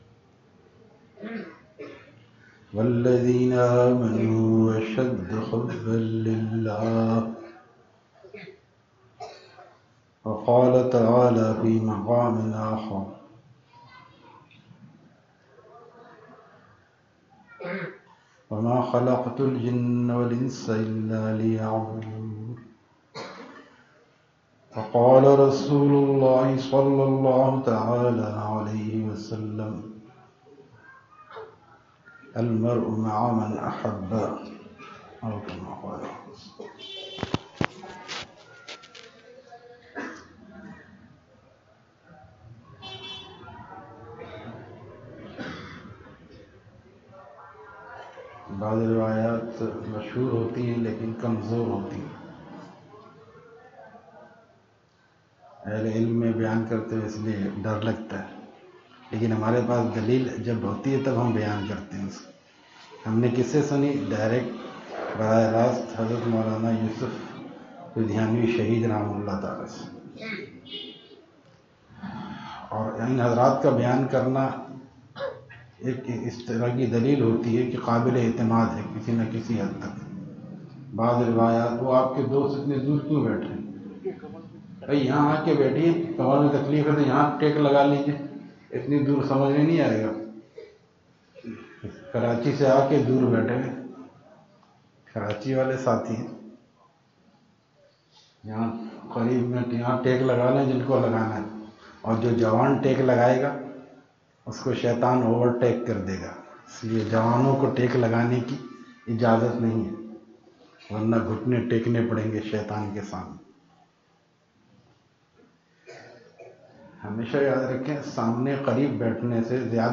Bayan at Madarsa Riaz Ul Uloom, Liaquat Colony, Hyderabad